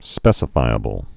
(spĕsə-fīə-bəl)